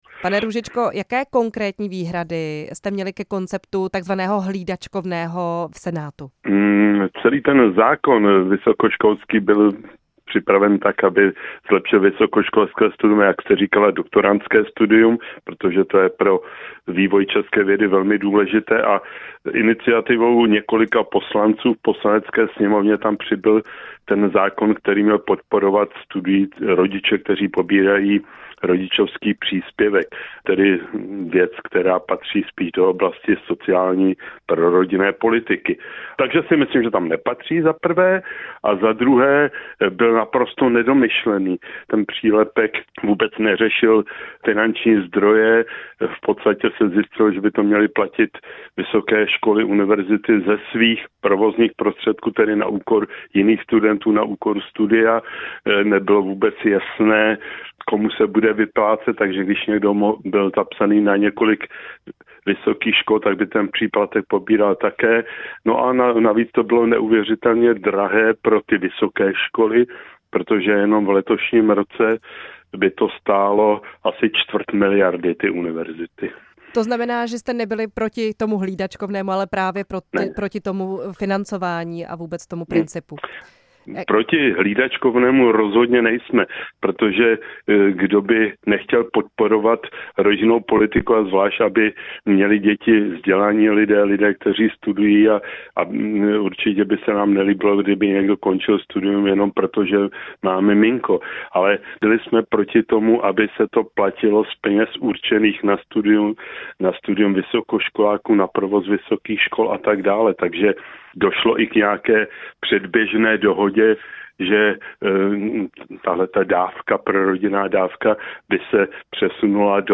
Ta se soustředí zejména na doktorské studium, poslanci ale řešili také návrh na zavedení příspěvku na hlídání pro studující matky, který neprošel přes Senát. Ve vysílání jsme novelu podrobněji probrali s šéfem senátního výboru pro vzdělávání Jiřím Růžičkou.
Jiří Růžička hostem vysílání Radia Prostor